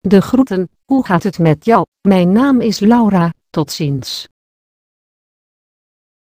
Monochrome Web vous propose d'�couter, via le lien ci-dessous, la d�monstration audio de : Laura (Nuance RealSpeak; distribu� sur le site de Nextup Technology; femme; hollandais)...